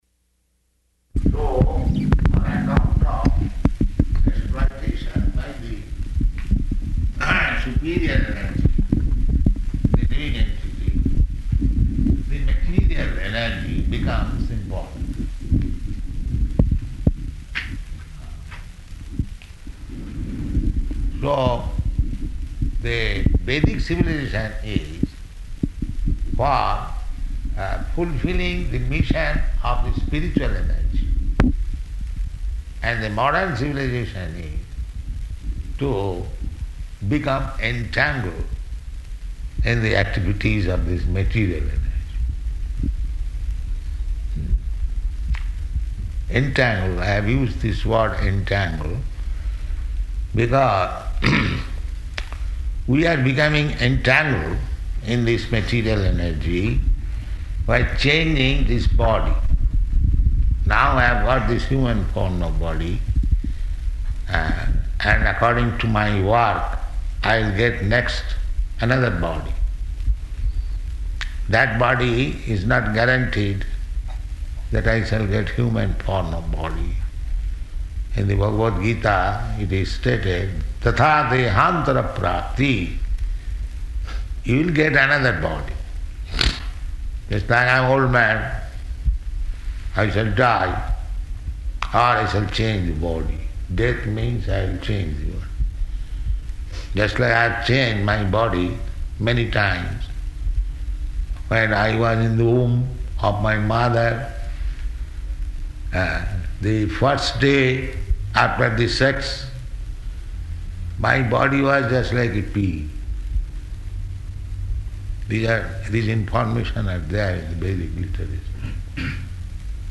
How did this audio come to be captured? Location: Johannesburg